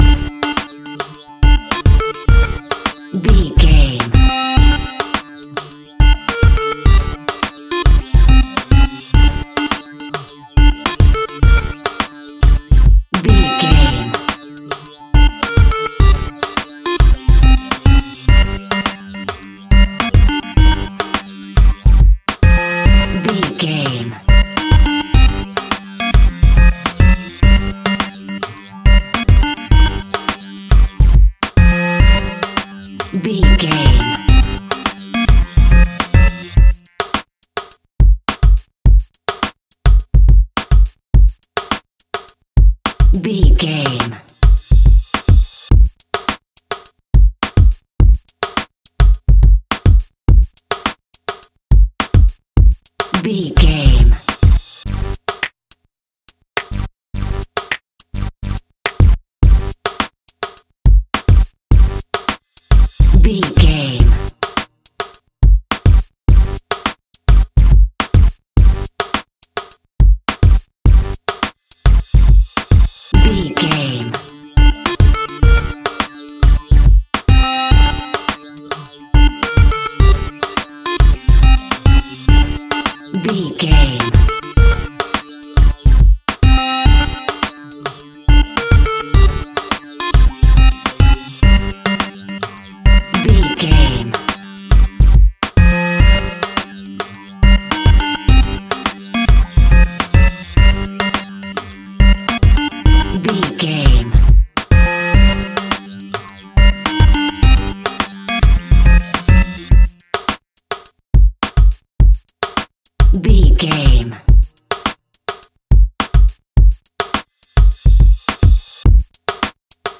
Aeolian/Minor
hip hop
hip hop instrumentals
funky
groovy
east coast hip hop
electronic drums
synth lead
synth bass